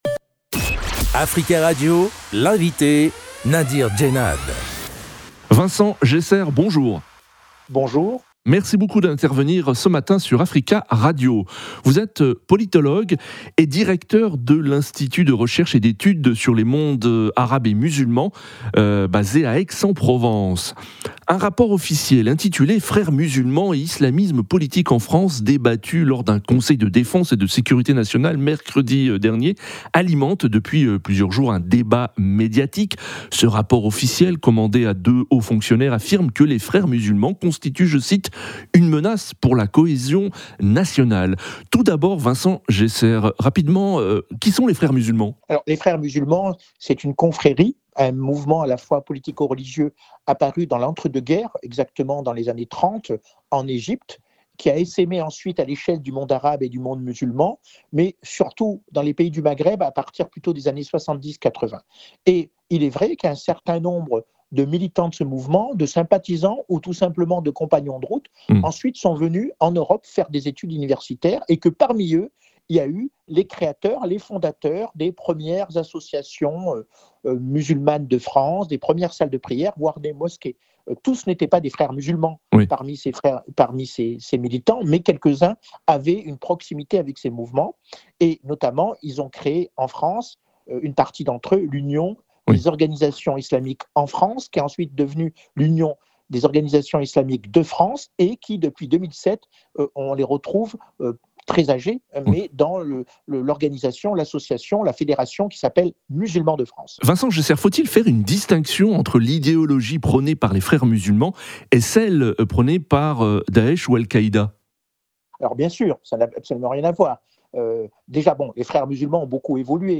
Actus.